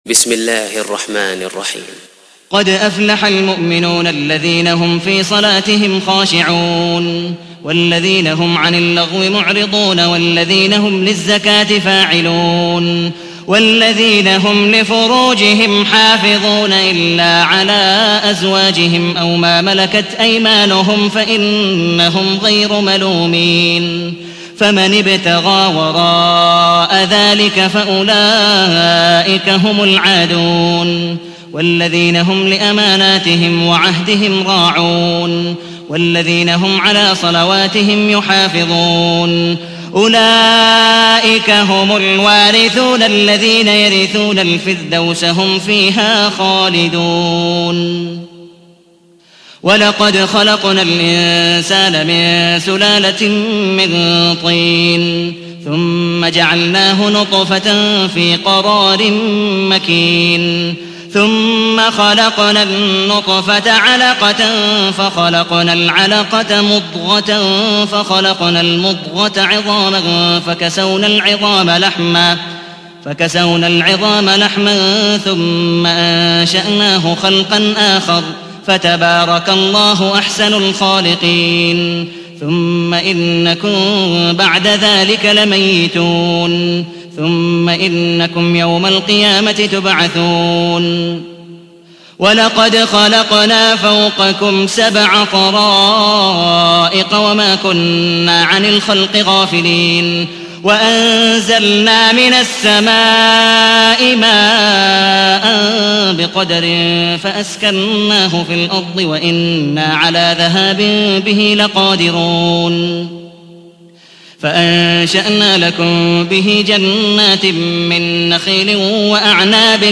تحميل : 23. سورة المؤمنون / القارئ عبد الودود مقبول حنيف / القرآن الكريم / موقع يا حسين